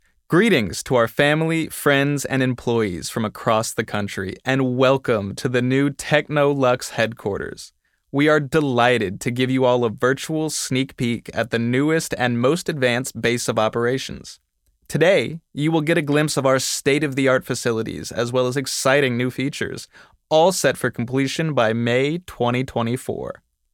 Male
Yng Adult (18-29)
Live Announcer
Words that describe my voice are Versatile, Calm, Natural.